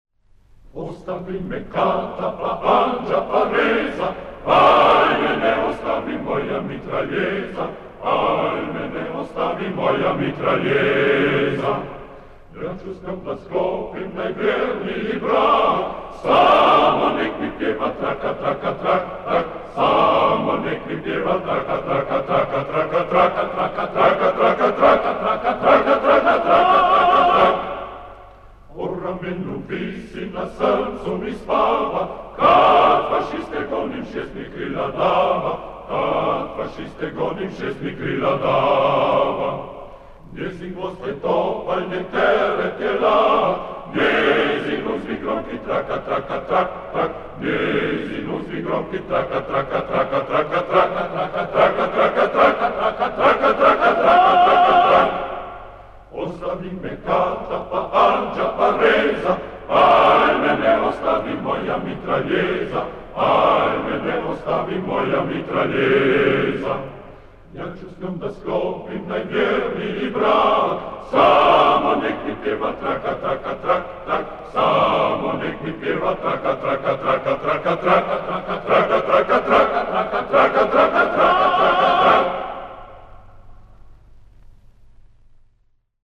Песня югославских партизан. Запись 1950-х - 1960-х гг.